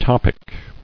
[top·ic]